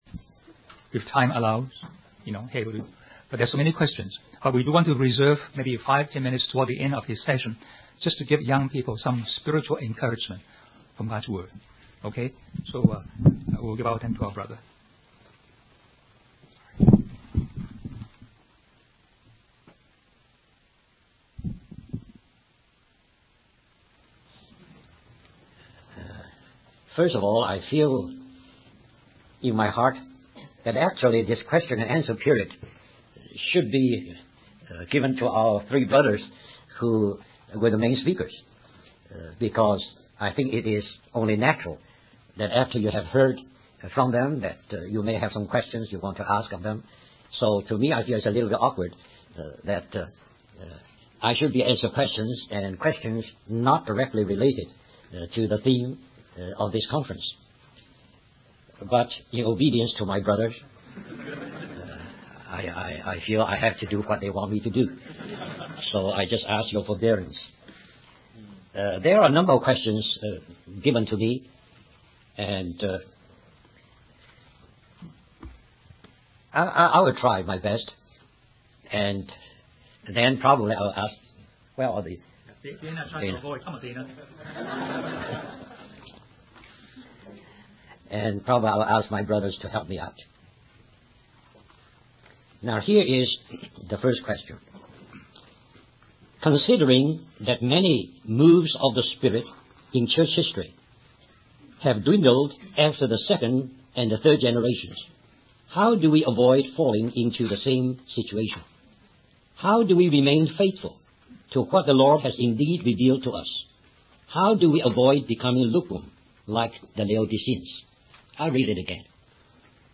1996 Christian Youth Conference